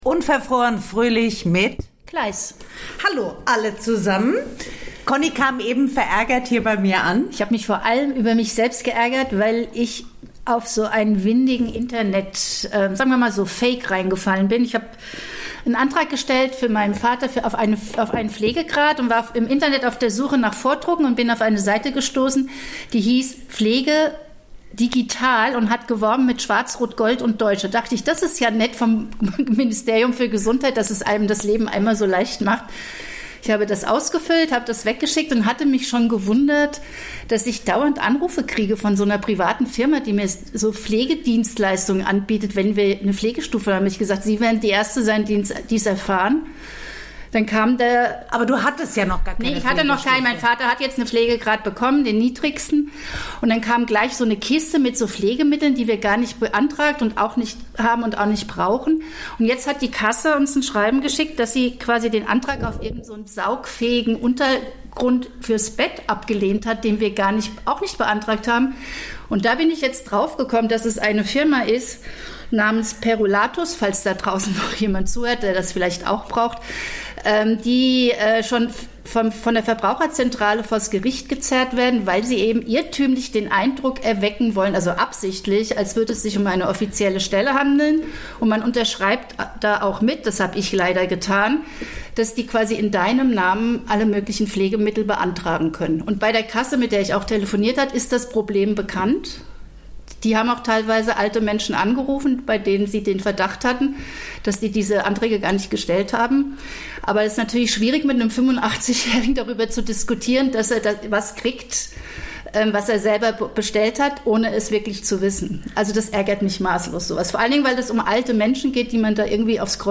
reden die beiden Podcasterinnen über eine andere Form des Empty nests, über Internetpülverchen, über Pflegefakes und Beautydruck für junge Frauen.